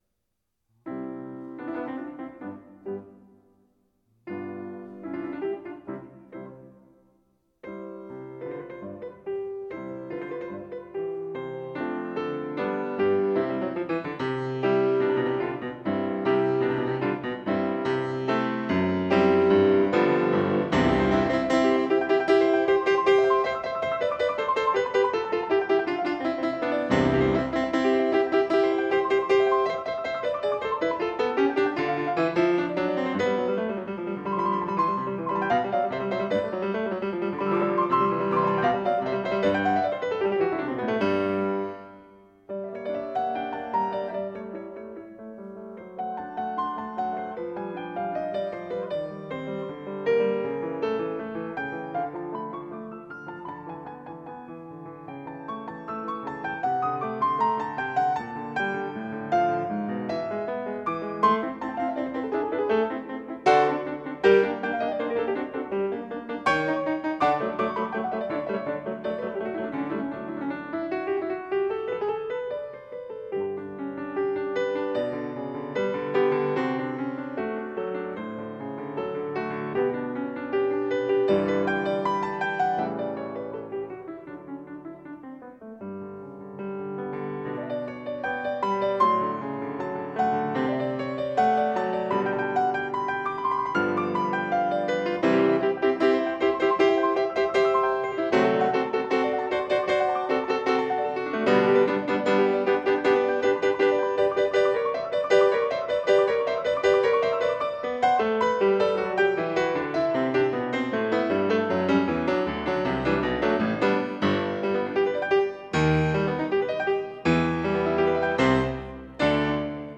Piano
Style: Classical